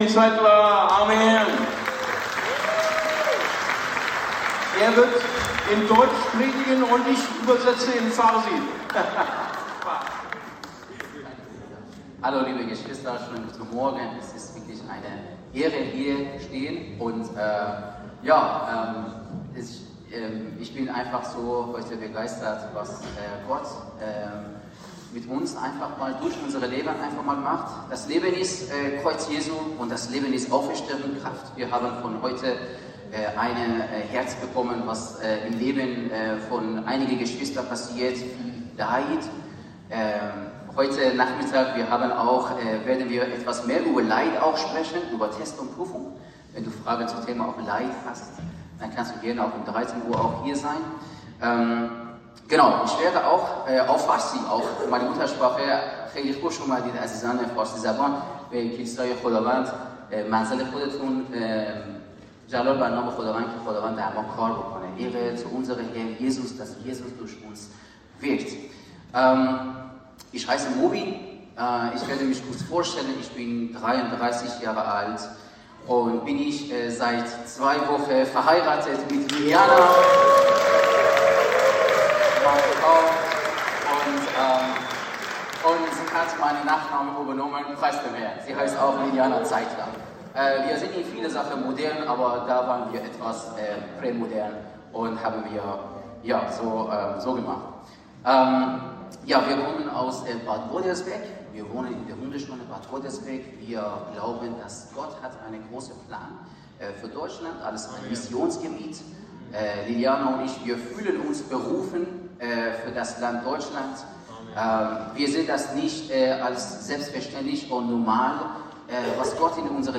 ICB Predigtreihe Römerbrief Das „5. Evangelium“: Ein Leben im Sieg! Freiheit finden in Jesus!